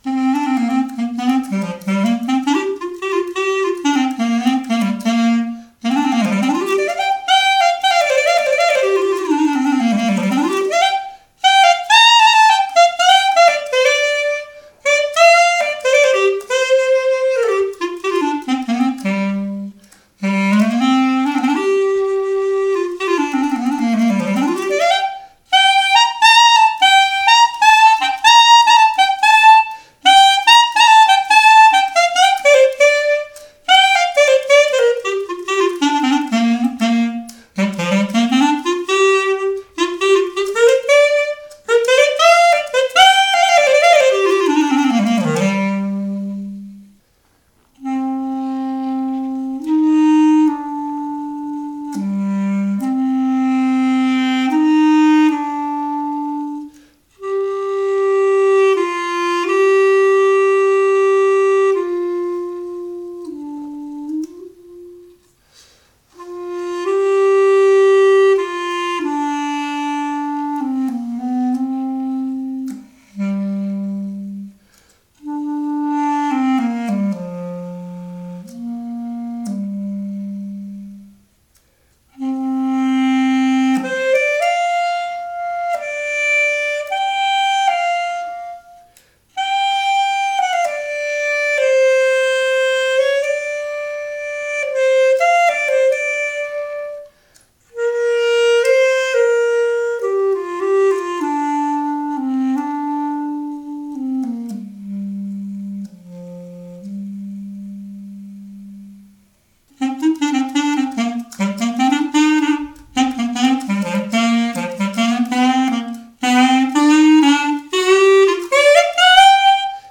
Was schließlich den Klang betrifft, so ist mir persönlich kein Unterschied zum Klang der Traditionsklarinette aufgefallen - abgesehen von einer größeren Lautstärke besonders im oberen Chalumeau-Bereich.